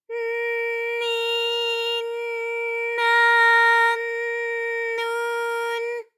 ALYS-DB-001-JPN - First Japanese UTAU vocal library of ALYS.
n_n_ni_n_na_n_nu_n.wav